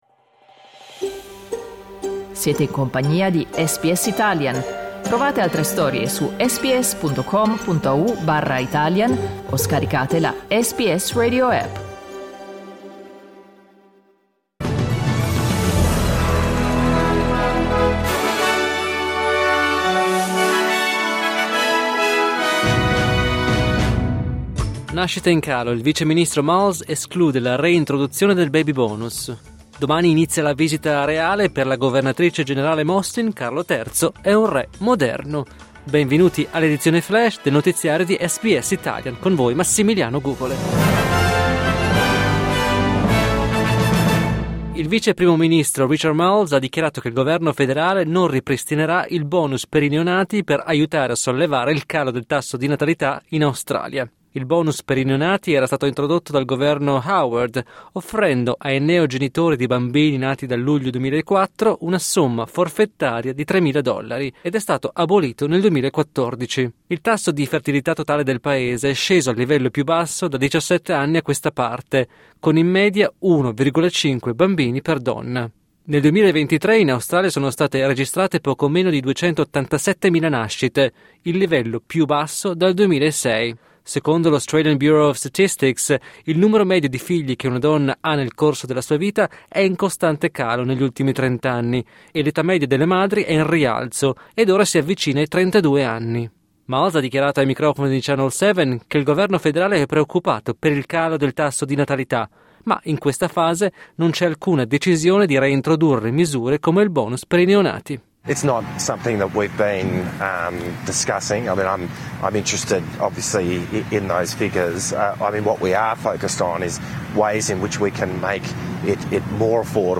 News flash giovedì 17 ottobre 2024
L’aggiornamento delle notizie di SBS Italian.